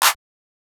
{Clap} MurdaClap.wav